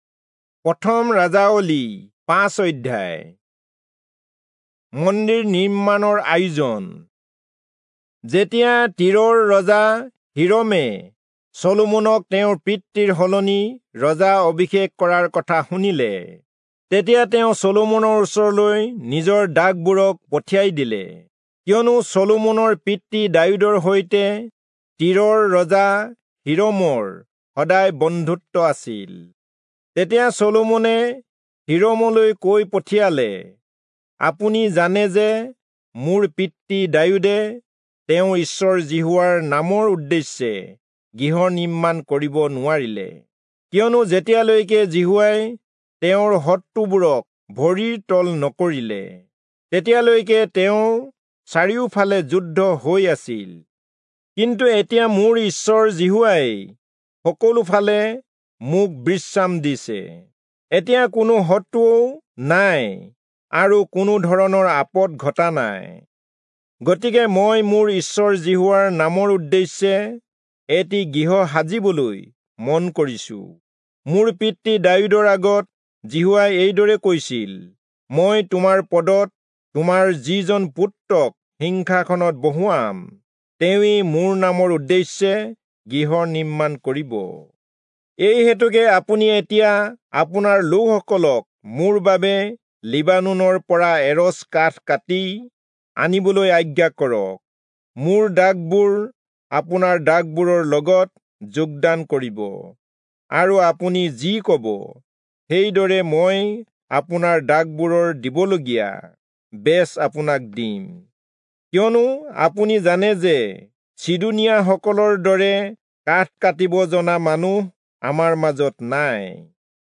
Assamese Audio Bible - 1-Kings 22 in Erven bible version